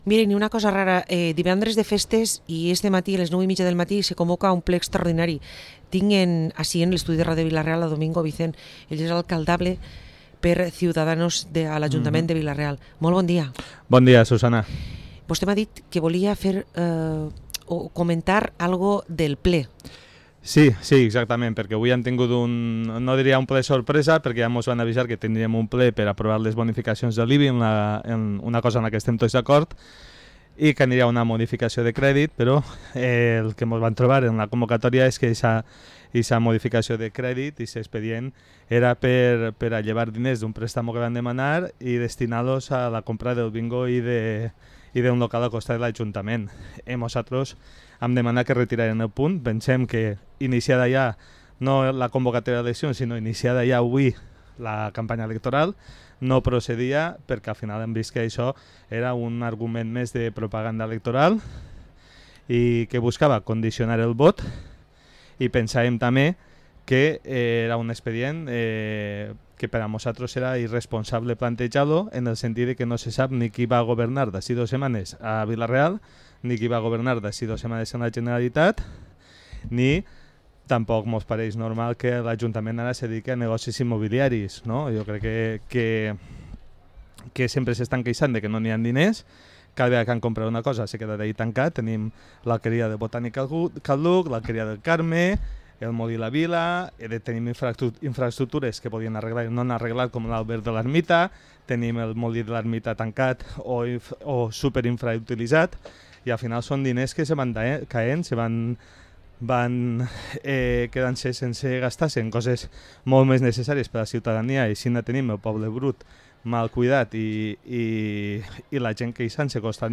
Entrevista a Domingo Vicent, portaveu de Cs i candidat a l´alcaldia Cs de Vila-real